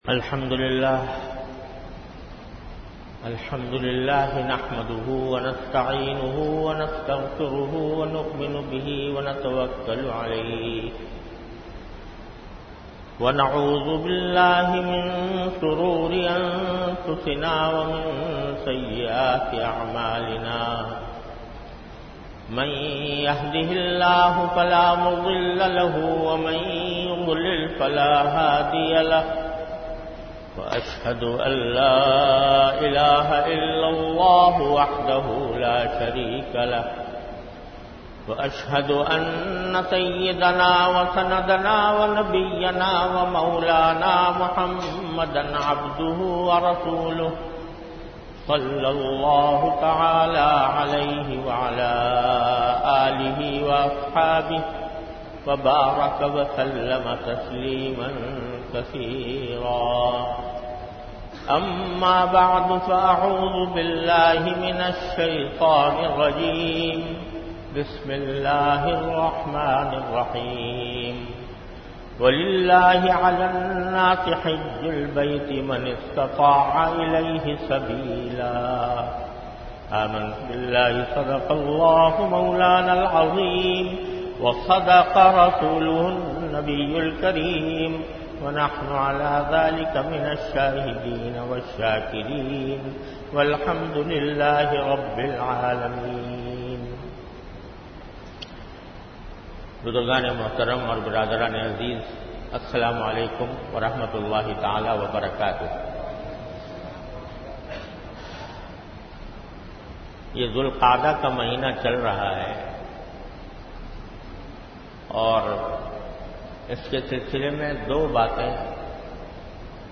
An Islamic audio bayan by Hazrat Mufti Muhammad Taqi Usmani Sahab (Db) on Bayanat. Delivered at Jamia Masjid Bait-ul-Mukkaram, Karachi.